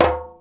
100DJEMB02.wav